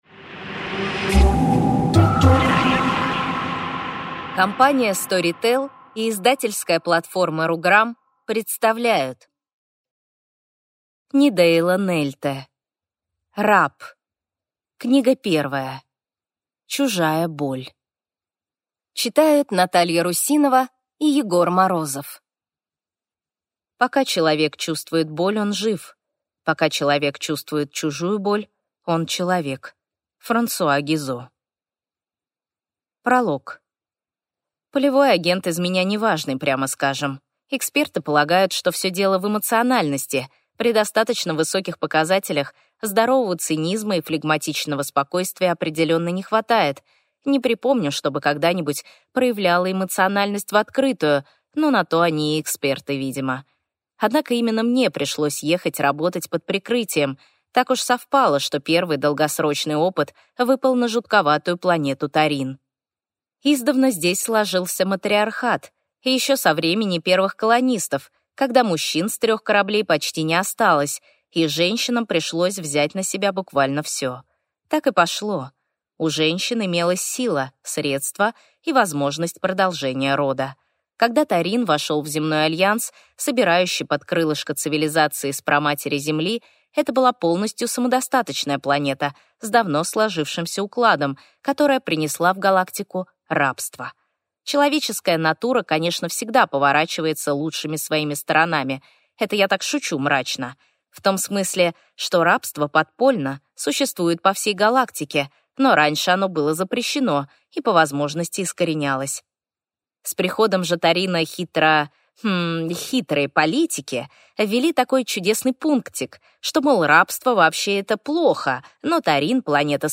Аудиокнига Раб. Книга 1. Чужая боль | Библиотека аудиокниг